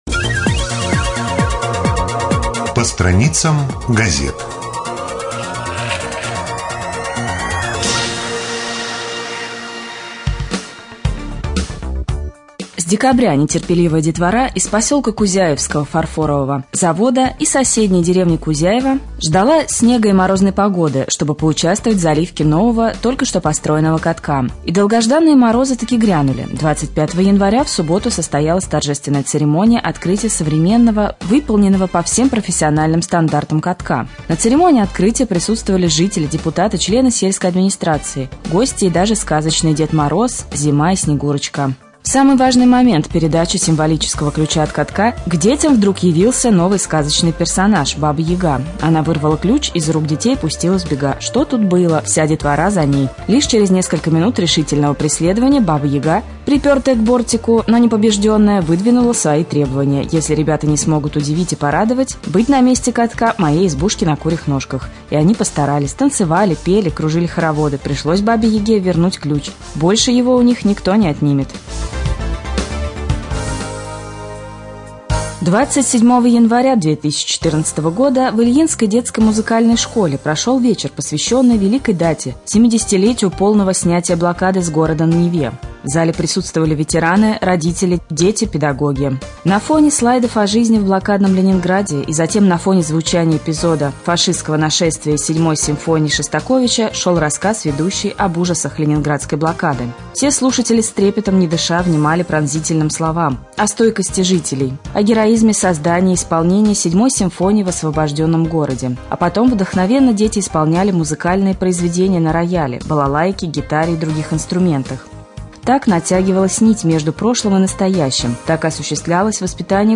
31.01.2014г. в эфире раменского радио - РамМедиа - Раменский муниципальный округ - Раменское